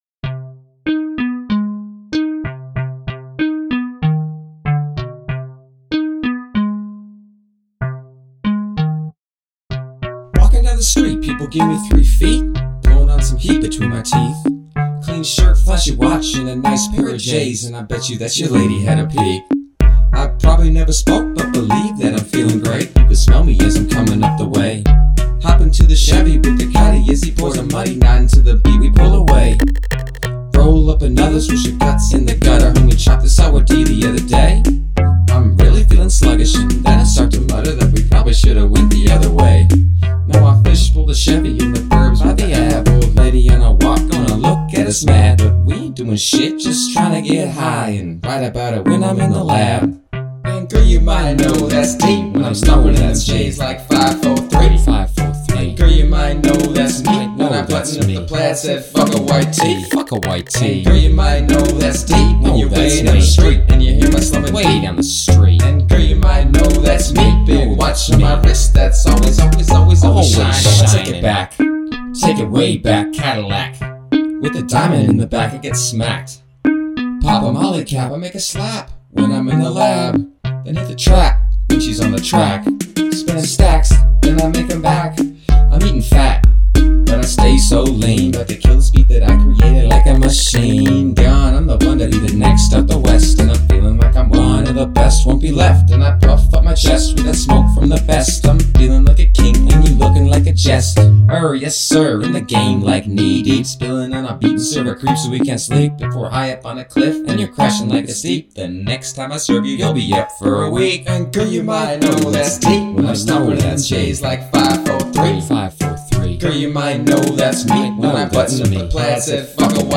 It's a little loose but there's no sections I hear that require attention, maybe the separation I've given the vocal parts has helped alleviate those issues? I did ZERO eq'ing, which is likely the only mix I've done in a decade where I didn't feel the need. There's a few spots where the vocals hit a little low on the pops (P's, B's) but they're well within my standards of being okay.